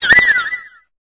Cri de Fluvetin dans Pokémon Épée et Bouclier.